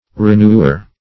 Renewer \Re*new"er\ (-?r)